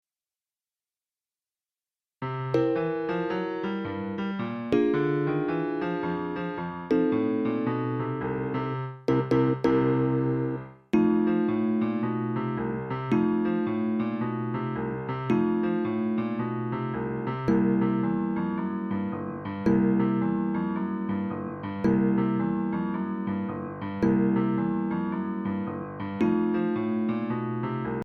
Mp3 Instrumental Track without melody for performance